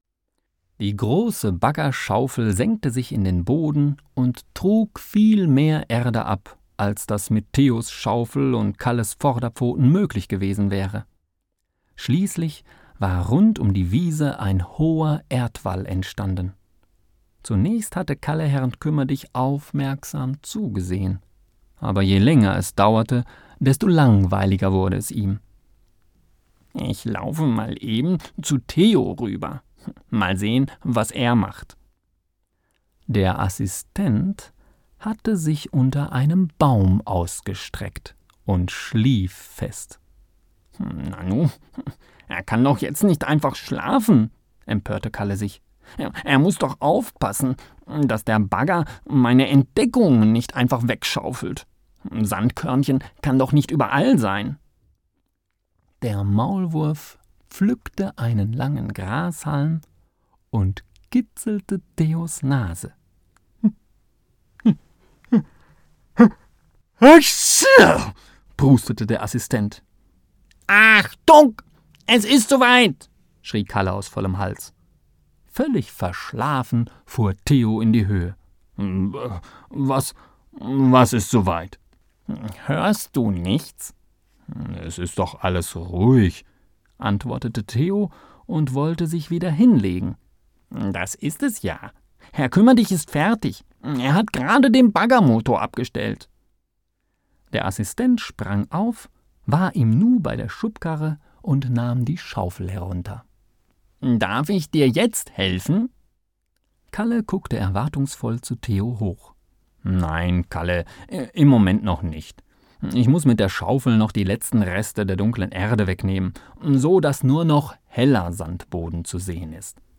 Lies mir bitte das Kapitel vor...